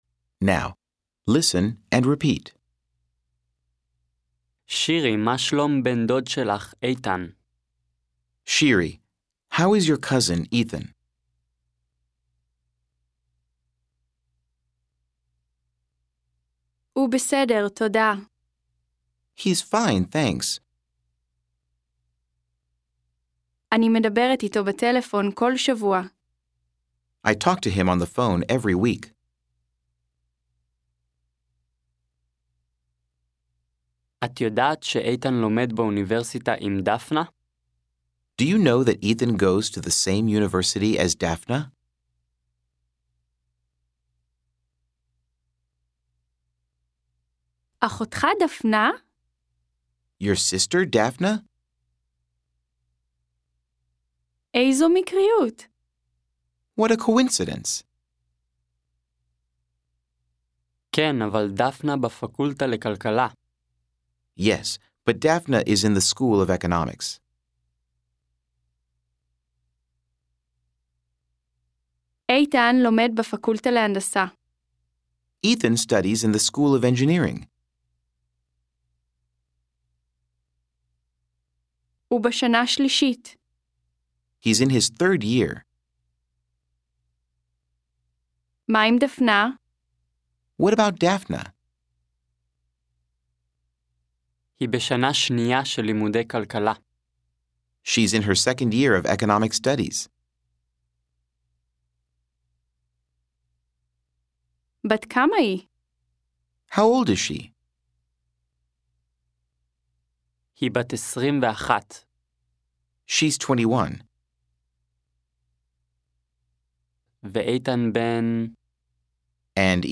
09 Dialogue 1b.mp3